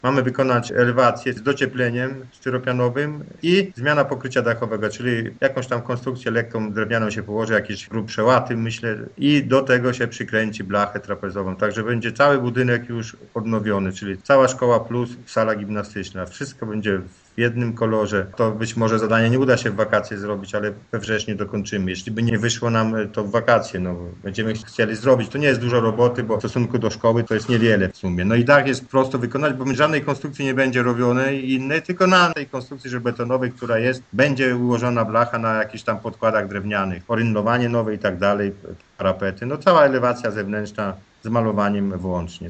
– Przetarg na wykonanie robót już został ogłoszony – informuje wójt Jan Filipczak: